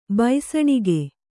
♪ baisaṇige